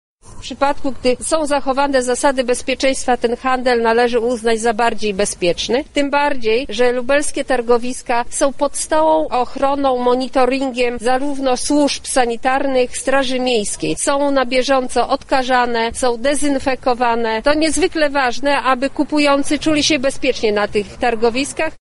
Istotną kwestią handlu na bazarach i targowiskach jest to, że odbywa się on na wolnym powietrzu – mówi poseł Marta Wcisło.